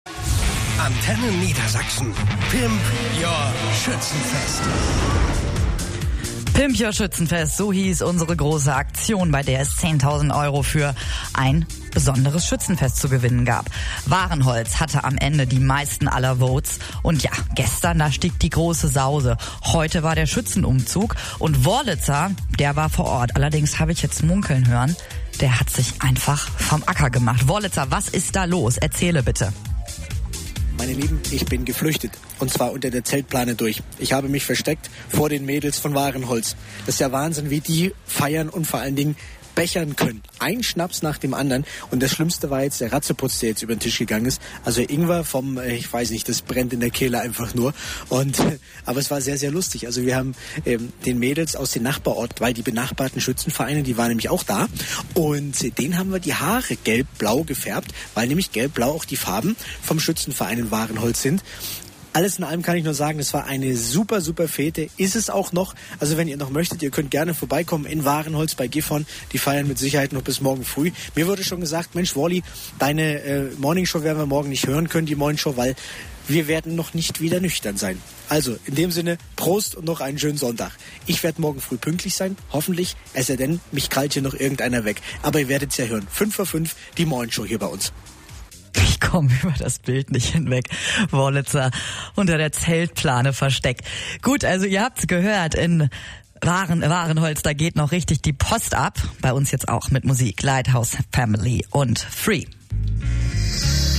Mitschnitt Antenne Niedersachsen Sonntag, 31.05. 16:46 Uhr